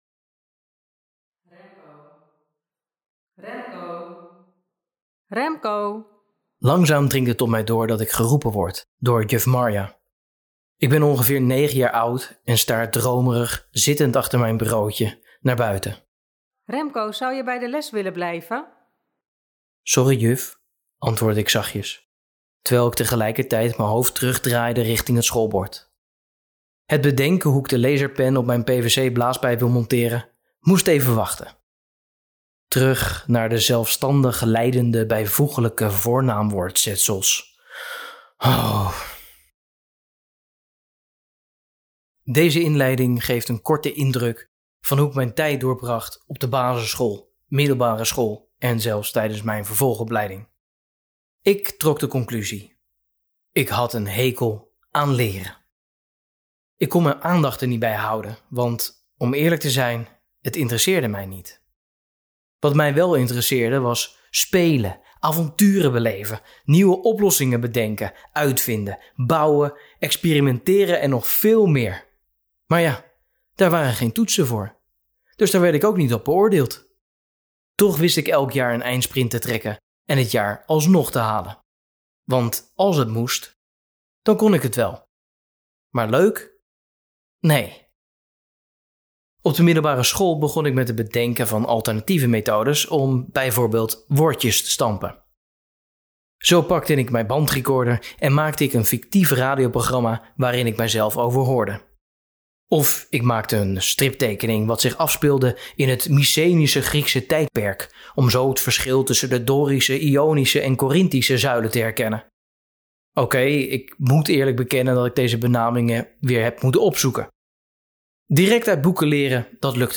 In het kader ‘Practice what you preach’, heb ik voor de geïnteresseerden, die ook liever luisteren naar een verhaal in plaats van lezen, mijn verhaal voor jullie ingesproken als luisterboek(je).